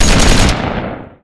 turret-4.wav